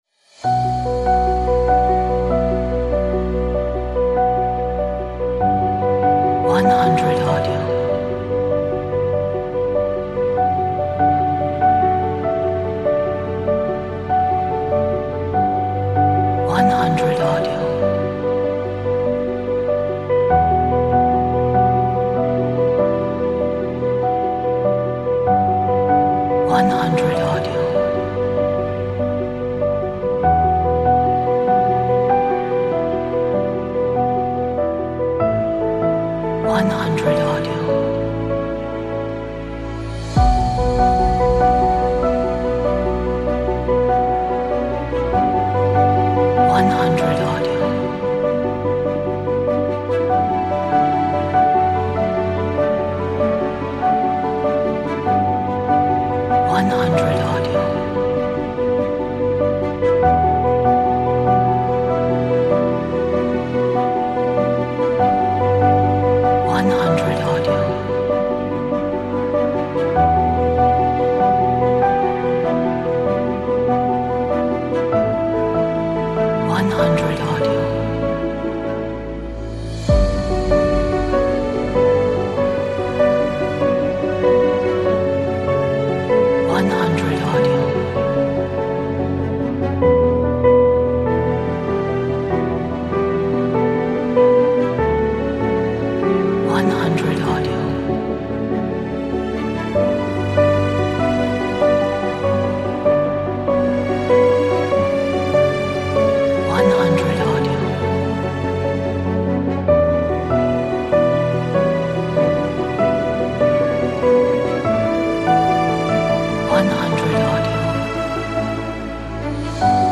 Romantic Ambient Cinematic